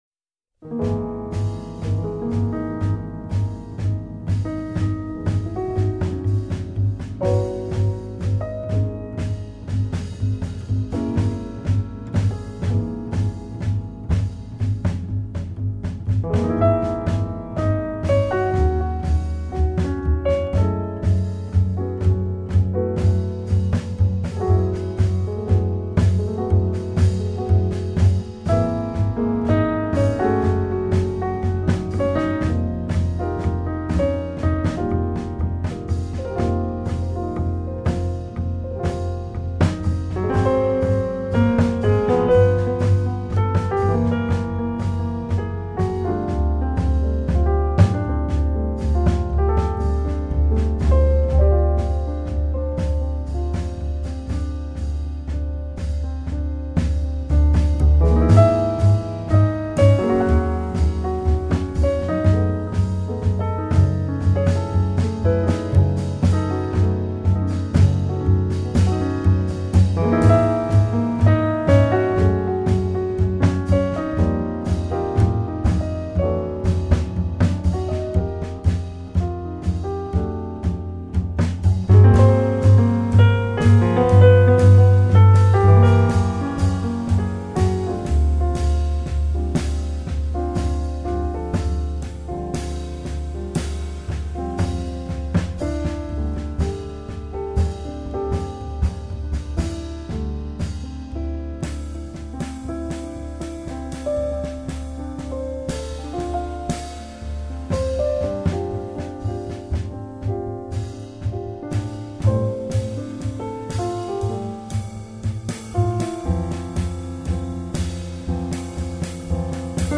Modern Jazz.